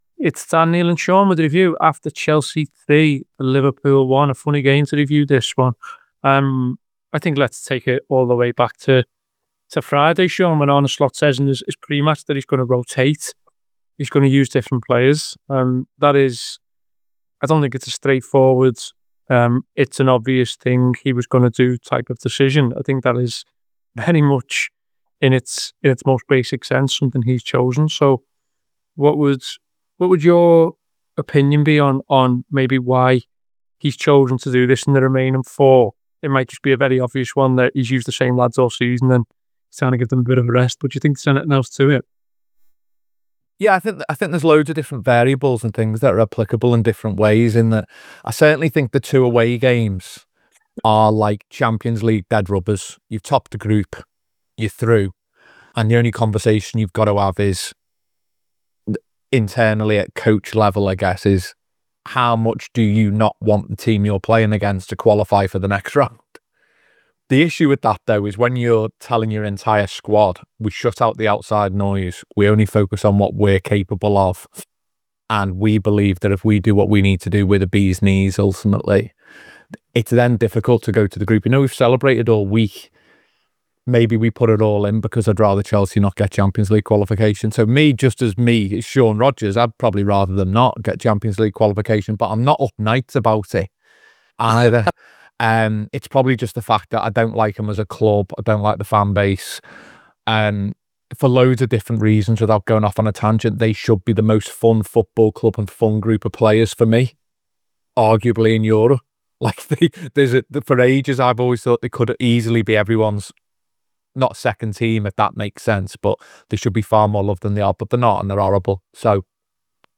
Below is a clip from the show – subscribe to The Anfield Wrap for more review chat around Chelsea 3 Liverpool 1…